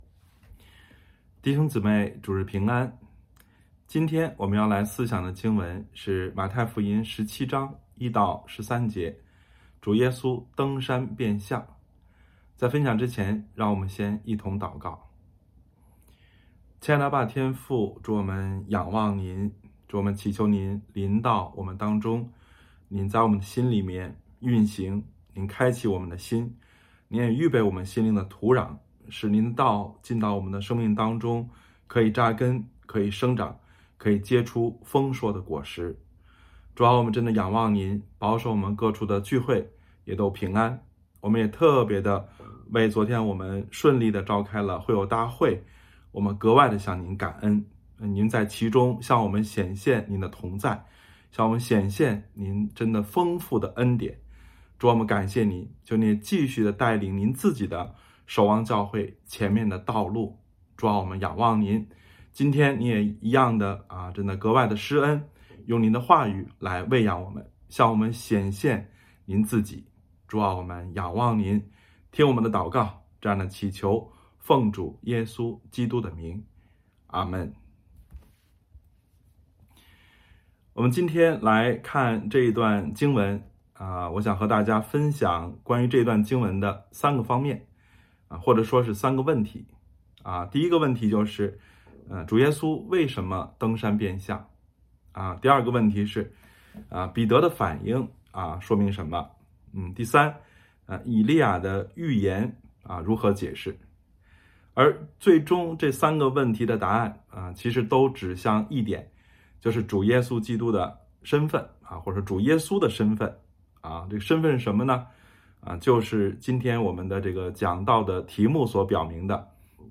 这两个儿子是哪一个遵行父命呢——2026年5月3日主日讲章